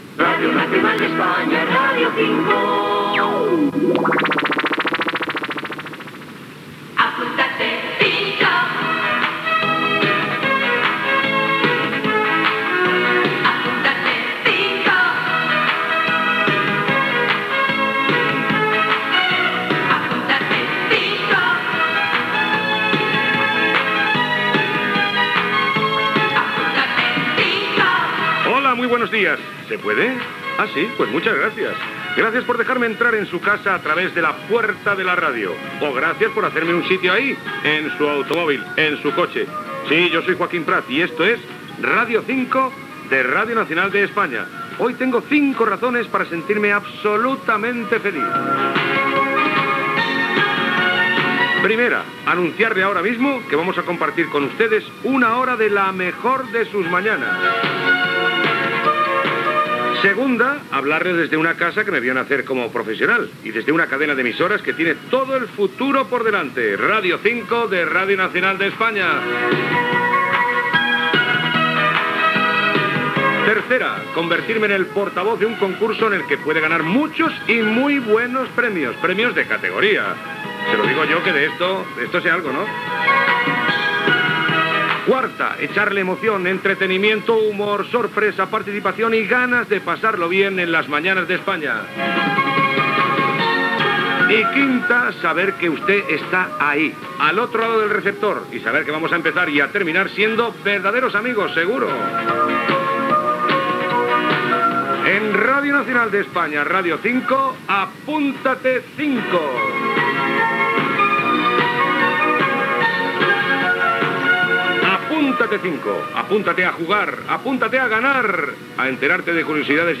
Indicatiu de l'emissora, careta del programa, presentació, cinc raons per estar feliç, explicació de la mecànica del concurs, indicatiu del programa, premis
Entreteniment